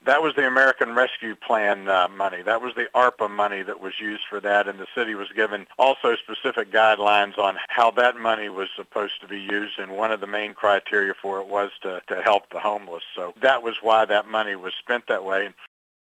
Mayor Ray Morriss called in to clarify that the funding for the Mission was actually from the federal government…